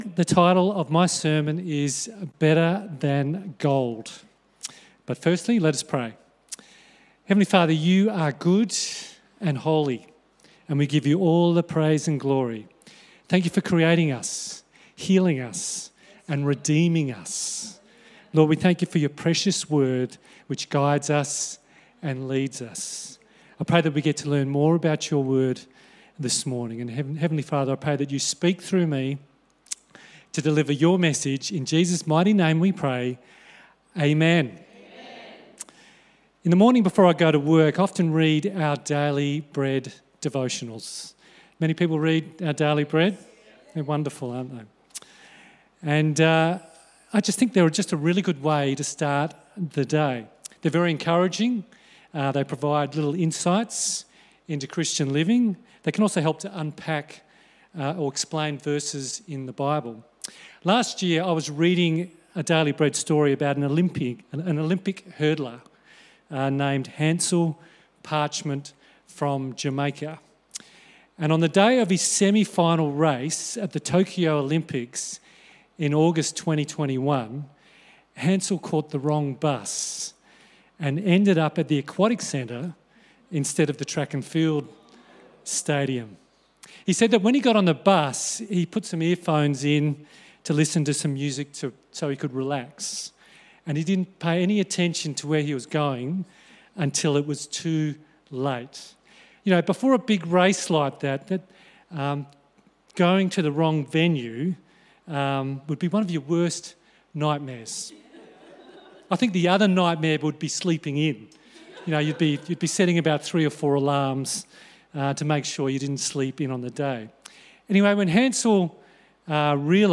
Sermon Transcript Good morning everyone.